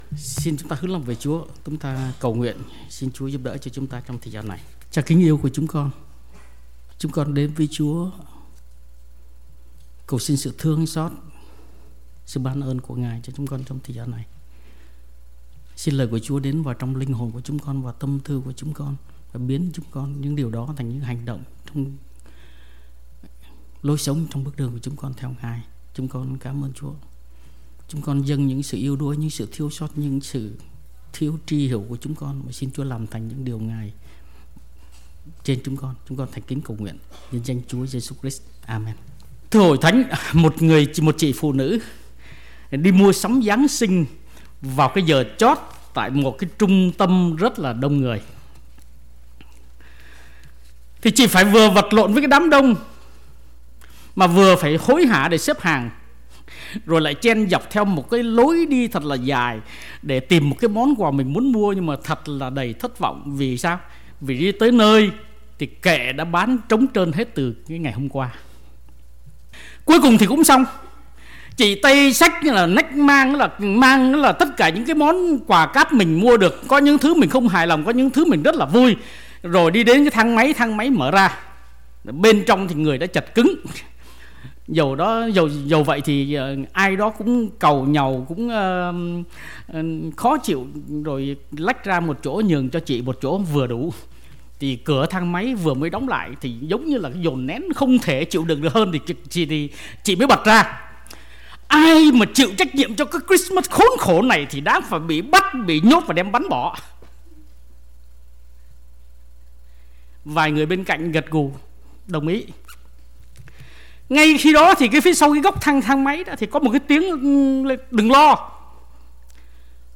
Bài Giảng | Hội Thánh Tin Lành Austin